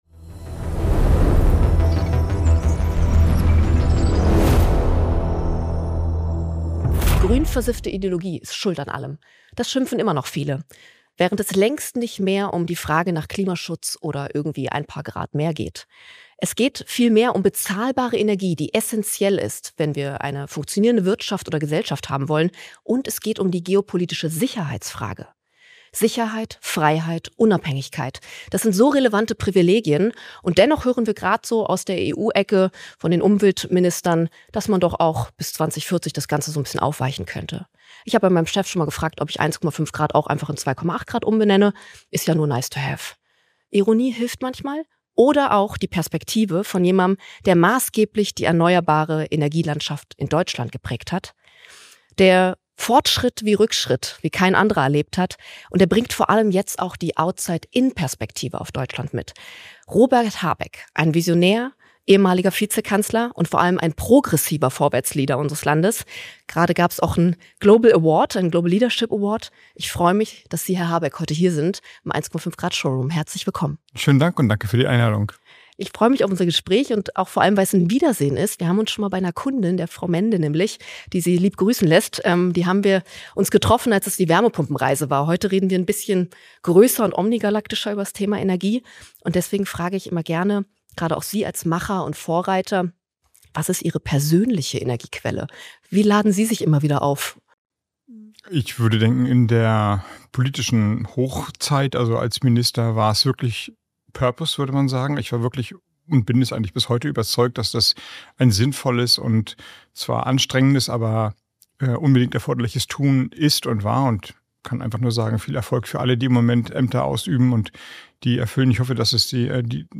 Ein authentisch, nahbares Gespräch als Podcastpremiere von Robert Habeck, in seiner neuen privaten Rolle.
Robert Habeck spricht offen, ehrlich und zum ersten Mal nicht als Politiker.